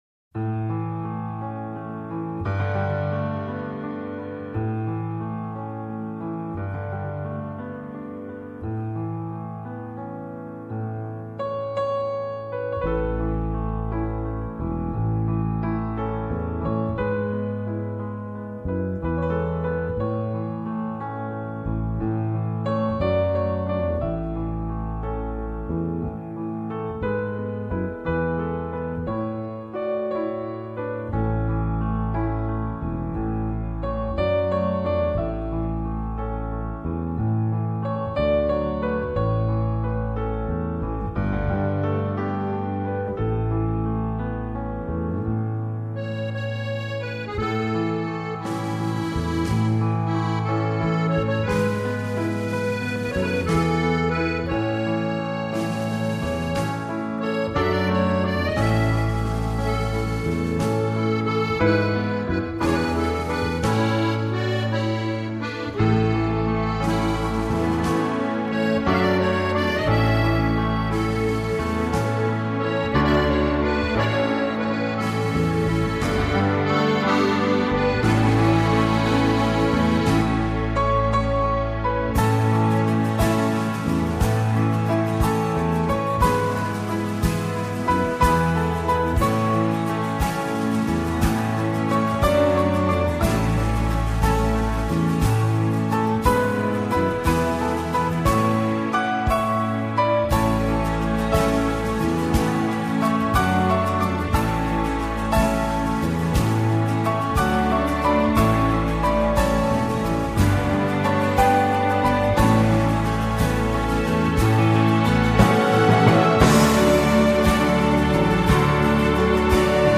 版本：钢琴
流畅的旋律，动情的音符，徜徉